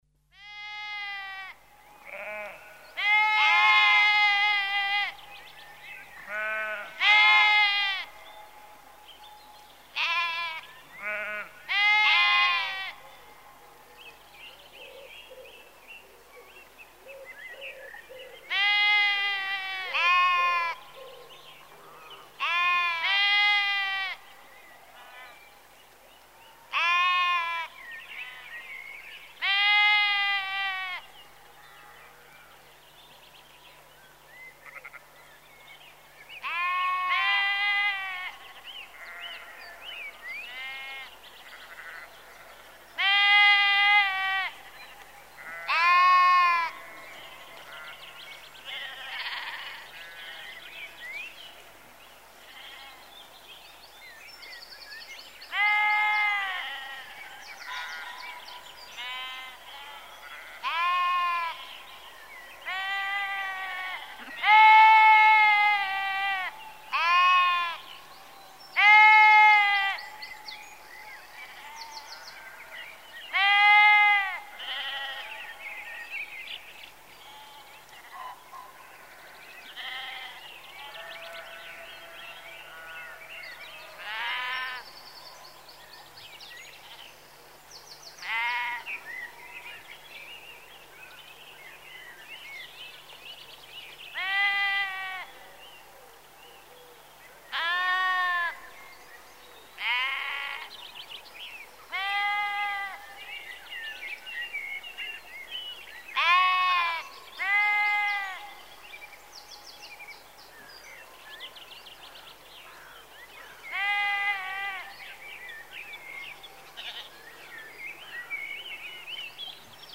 Звуки овцы
Звуки блеяния овец в природе